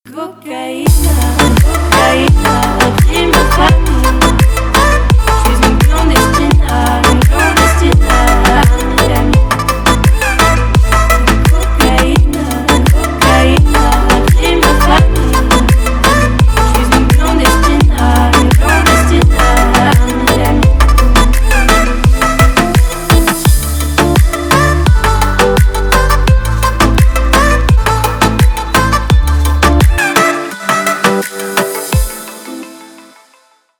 Ремикс # Поп Музыка
тихие